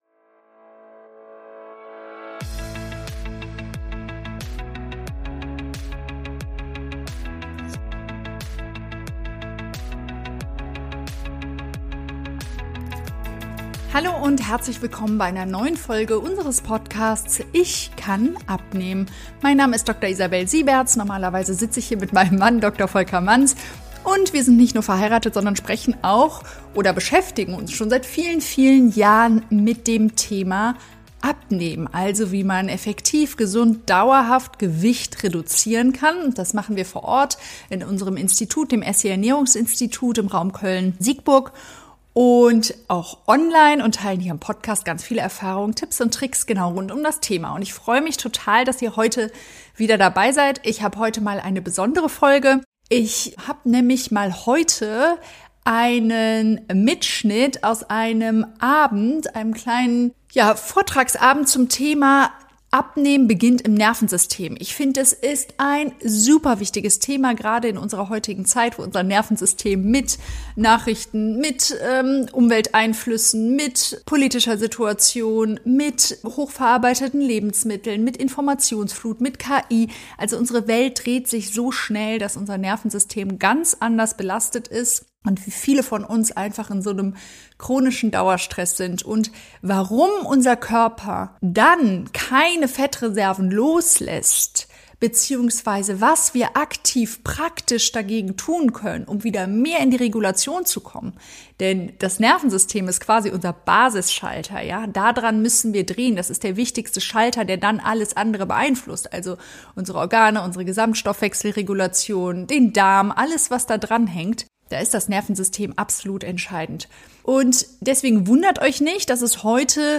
Live-Mitschnitt aus einem Vortragsabend